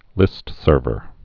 (lĭstsûrvər)